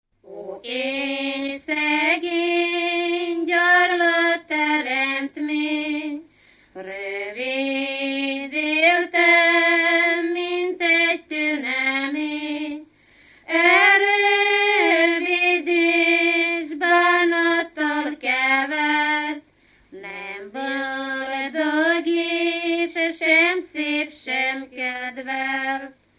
Dunántúl - Verőce vm. - Lacháza
ének
Stílus: 8. Újszerű kisambitusú dallamok
Kadencia: 2 (2) 3 1